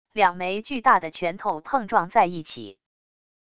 两枚巨大的拳头碰撞在一起wav音频